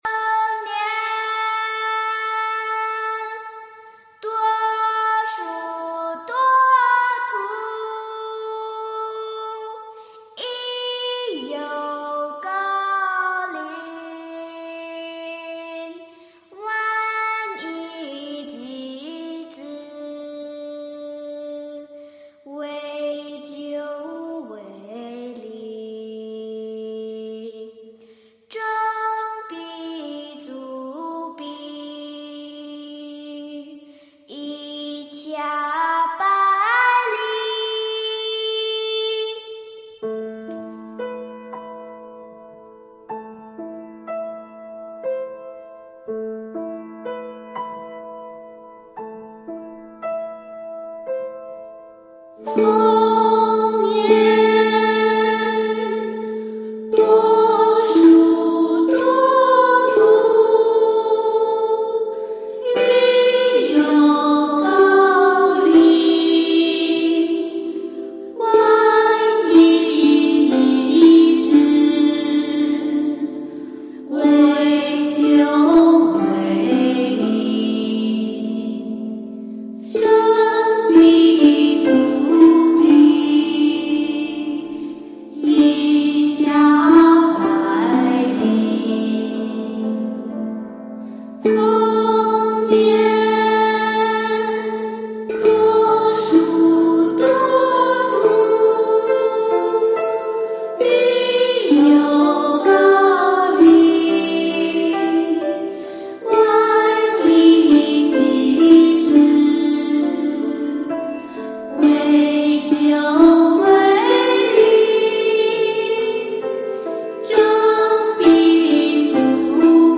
诗词吟诵
现场吟诵篇目二  ：